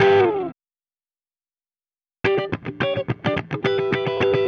Retro Funkish Guitar 01b.wav